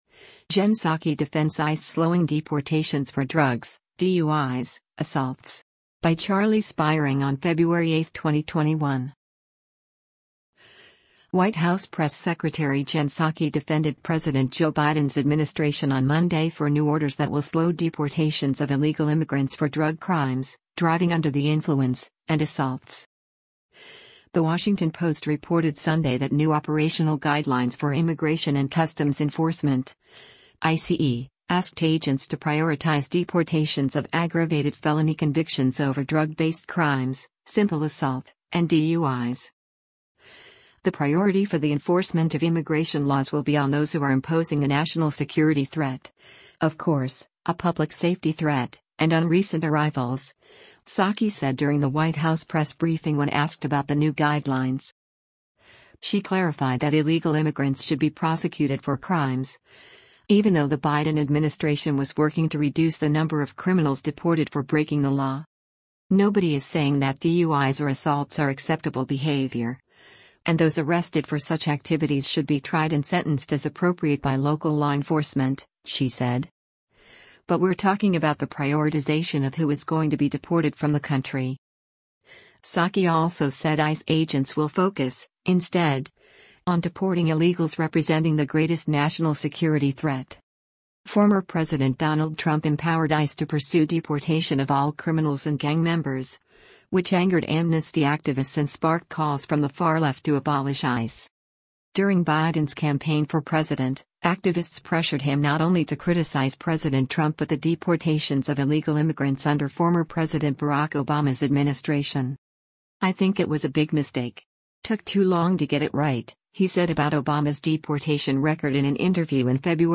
“The priority for the enforcement of immigration laws will be on those who are imposing a national security threat, of course, a public safety threat, and on recent arrivals,” Psaki said during the White House press briefing when asked about the new guidelines.